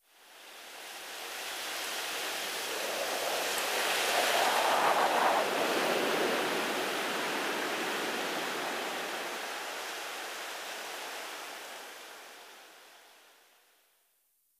windwhistle7.ogg